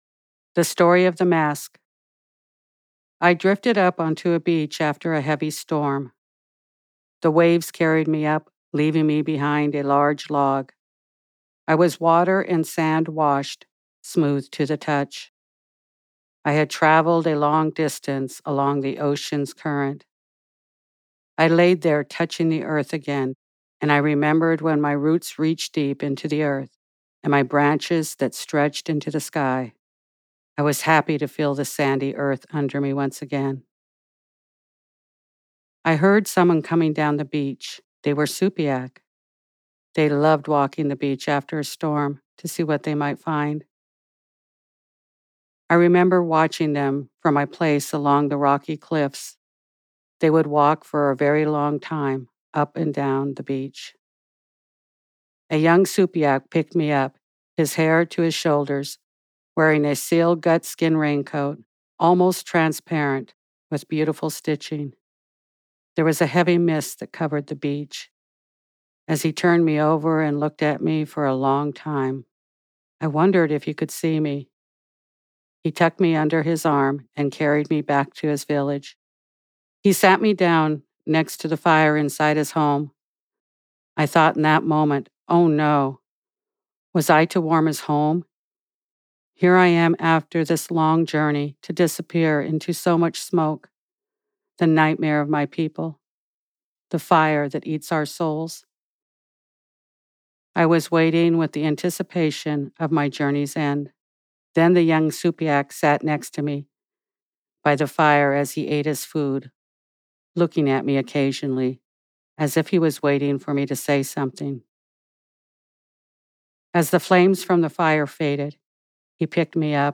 / Storyteller